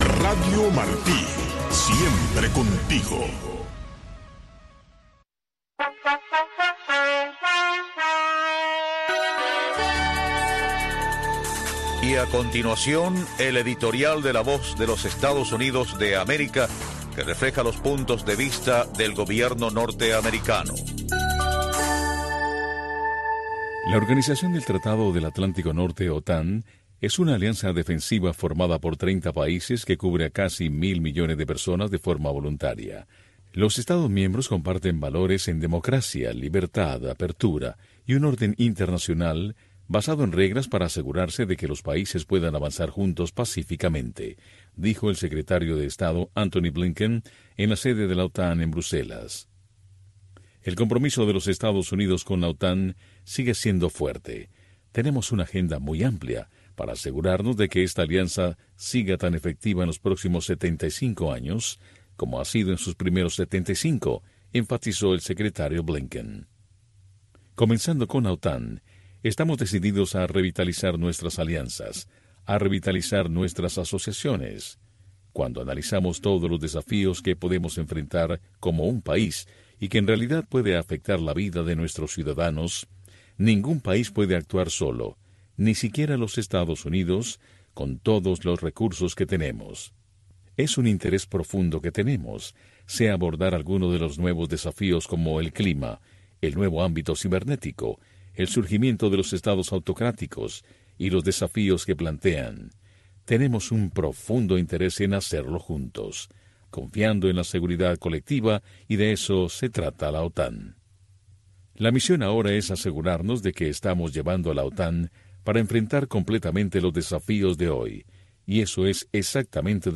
revista de entrevistas